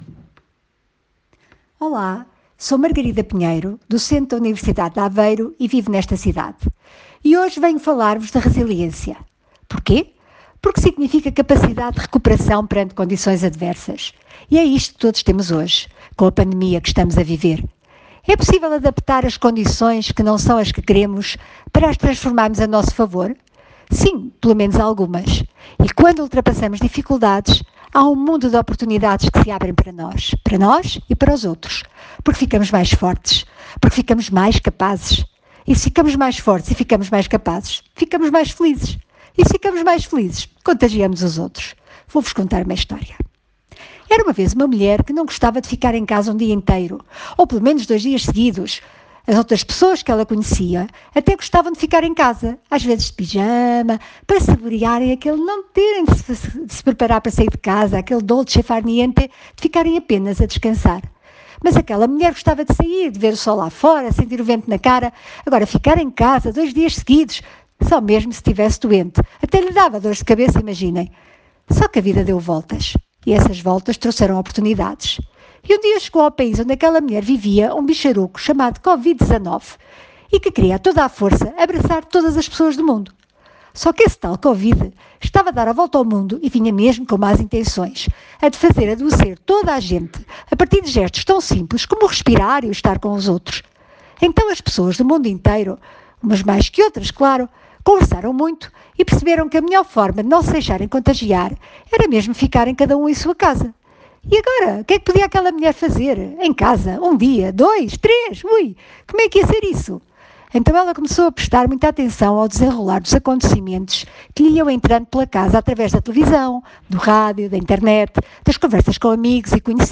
Disponibilizamos o podcast que foi transmitido na Rádio TerraNova, no passado dia 24 de abril, entre as 10h45 e as 11h00.